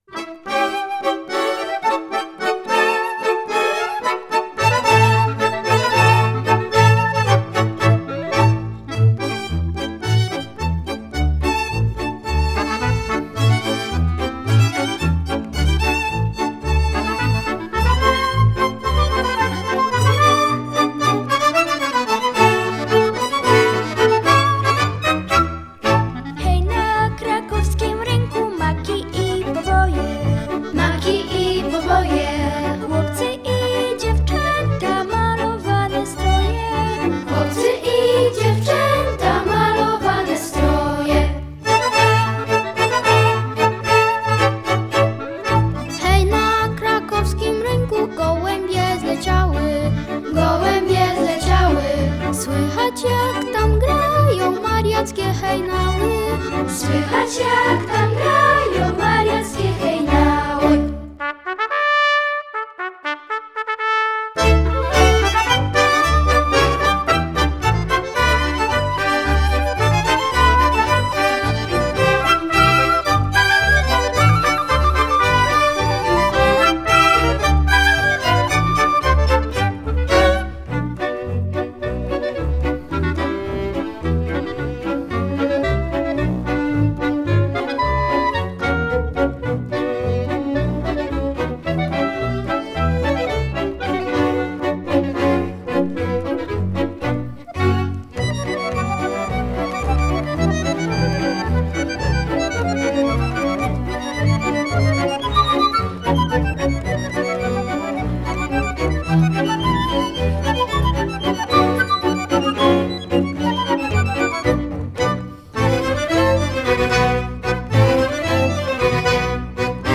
flet
trąbka
altówka
kontrabas
akordeon.